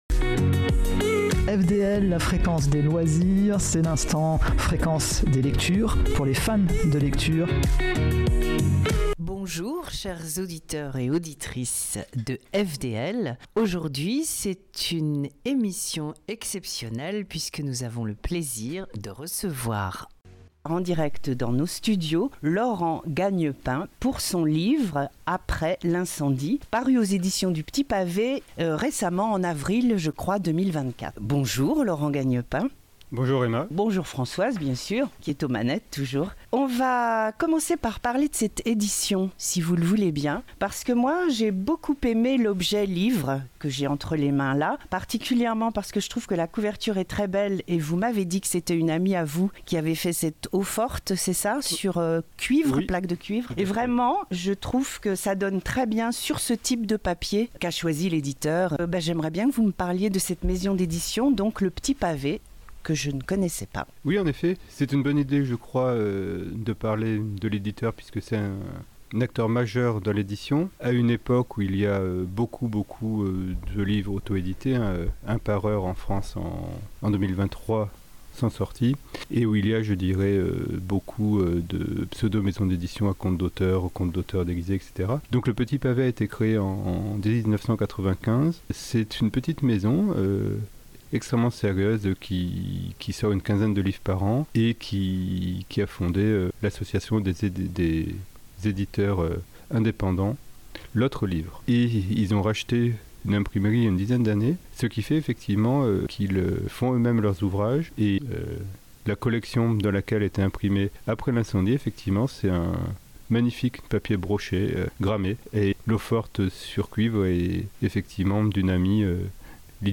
Retrouvez ici mon interview sur FDL